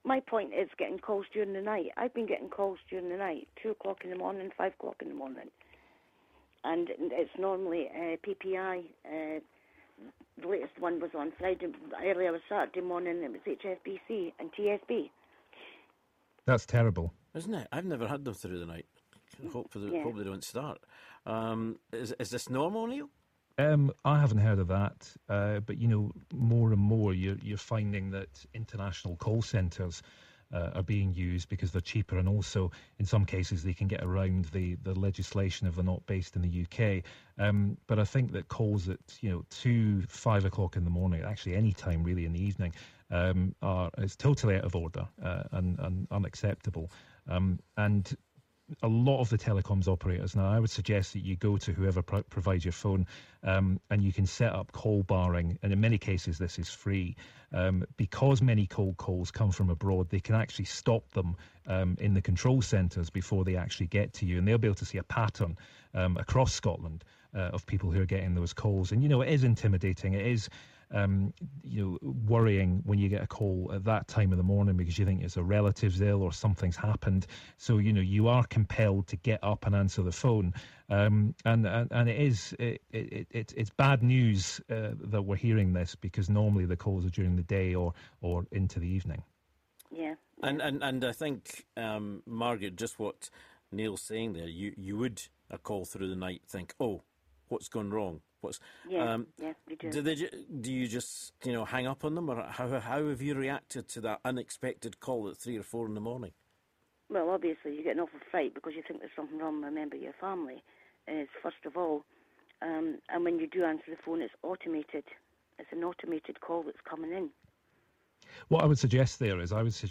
taking your calls on nuisance calls